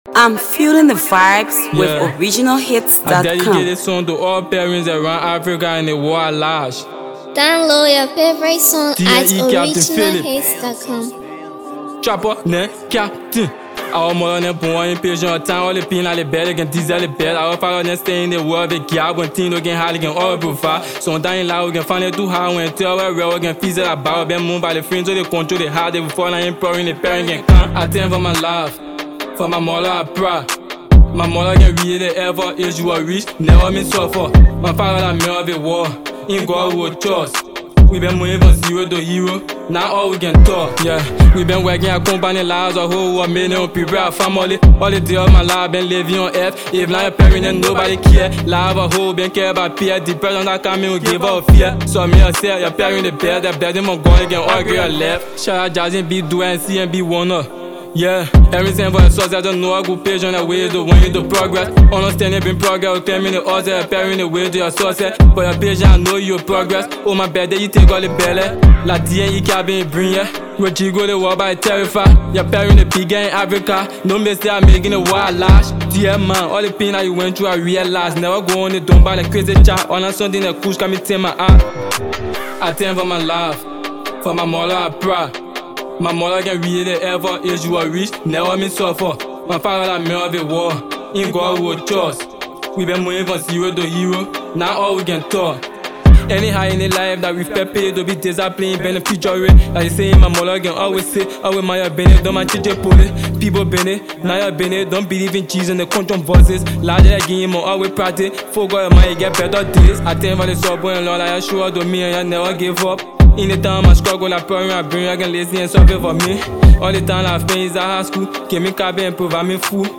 studio jam